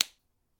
ソース類キャップ閉
seasoning_lid4.mp3